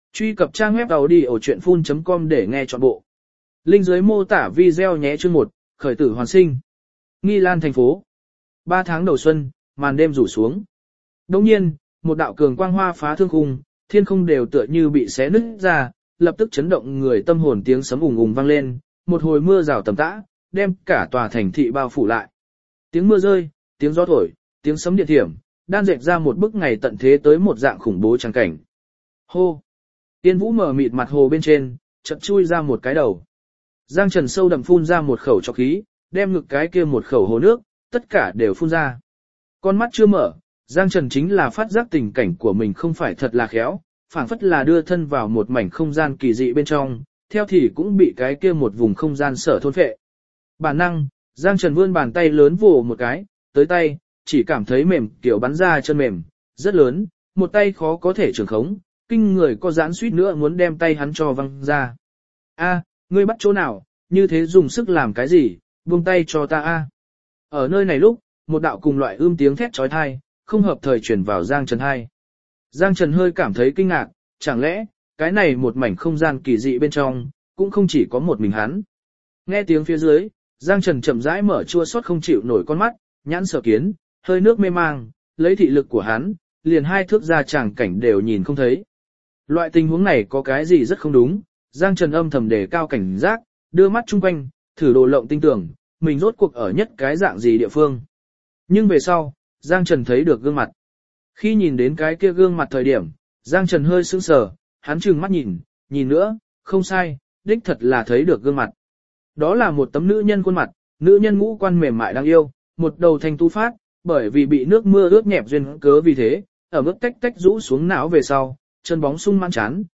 Thiên Tài Tà Thiếu Audio - Nghe đọc Truyện Audio Online Hay Trên TH AUDIO TRUYỆN FULL